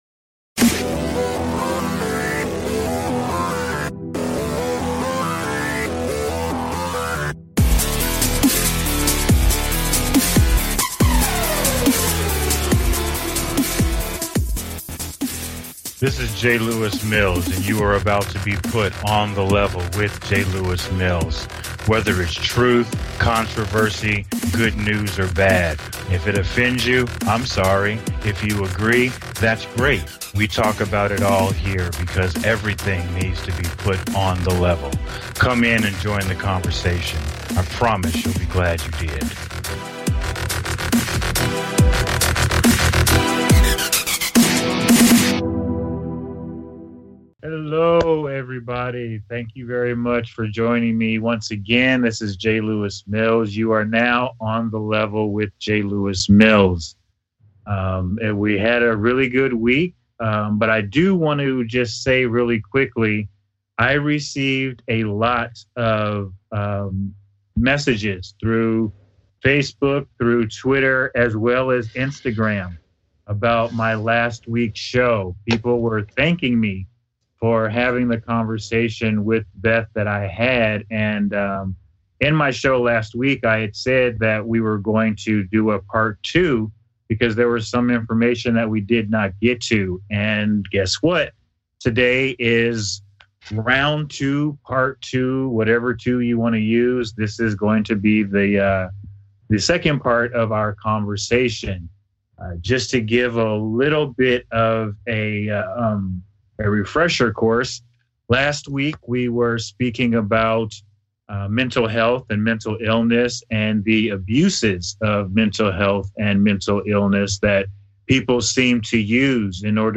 Talk Show Episode, Audio Podcast, On The Level and Guest